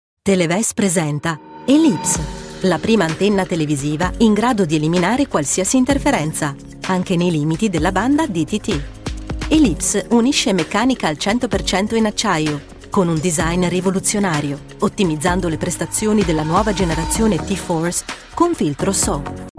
Professional singer and Italian voice talent whose expressive and versatile voice can range from smooth and sexy to friendly and upbeat, from warm and compassionate to authoritative and professional and is ideal for radio and TV commercials, telephone on hold, e-learning and corporates.
Sprechprobe: Industrie (Muttersprache):